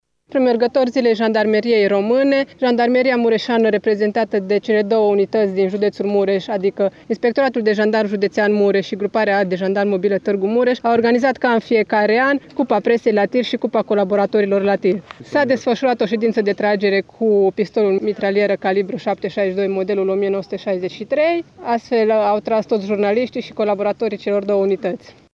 Cupa Presei la Tir, ediția 2016, s-a desfășurat astăzi la Poligonul de la Sângeorgiu de Mureș.